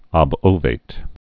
(ŏb-ōvāt)